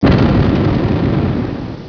explosion2.wav